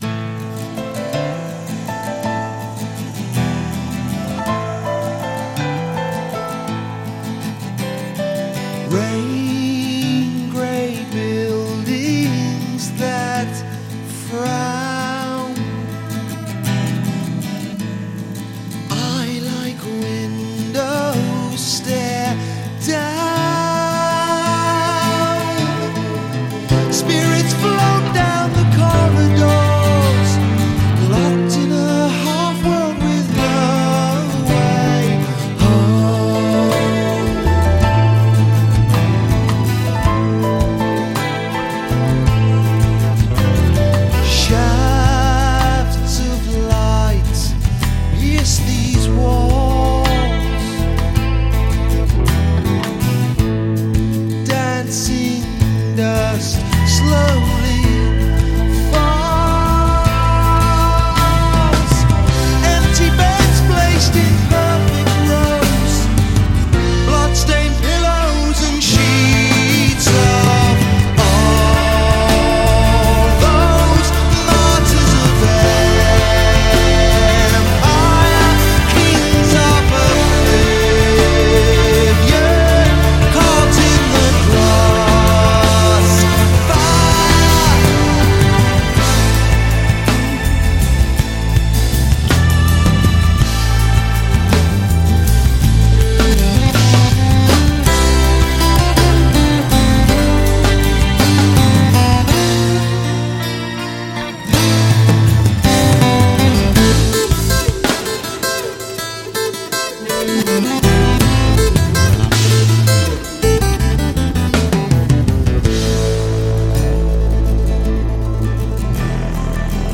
crossover prog
who plays piano on this track